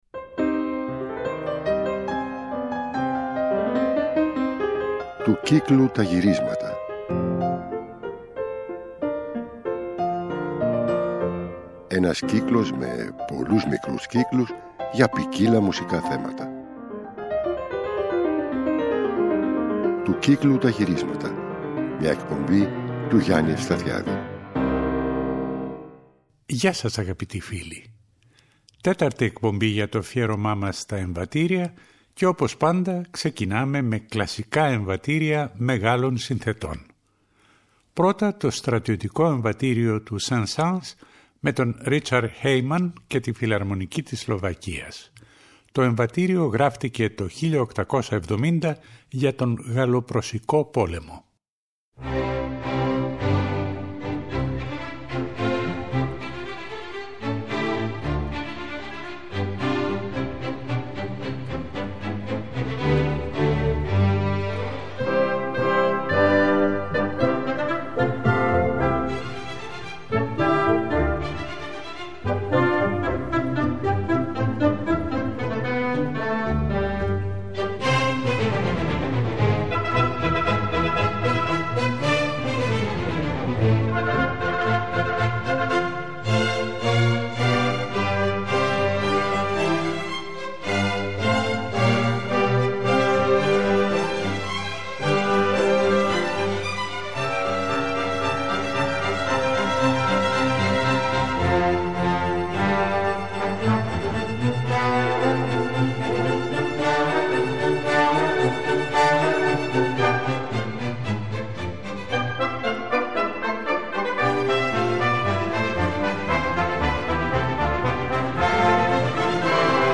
Αφιέρωμα στα Εμβατήρια – Μέρος 4